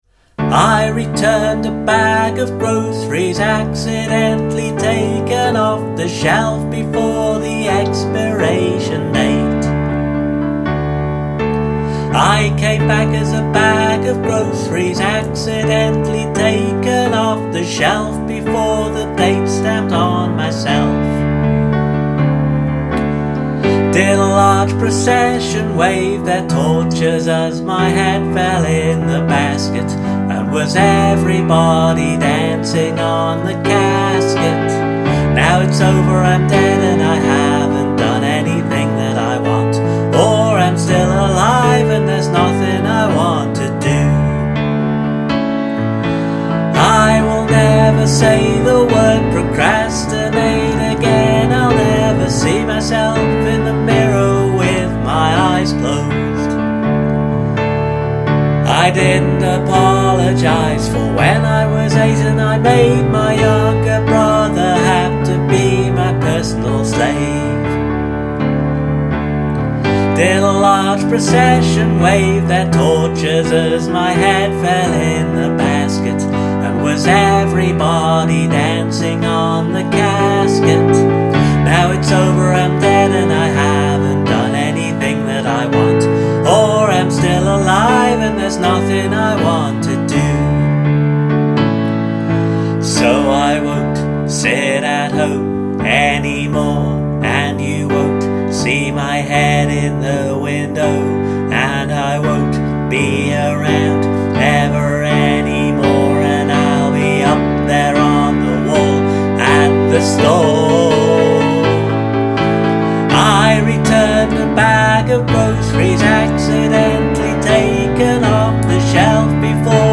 I thought I’d try doing this one on keyboard.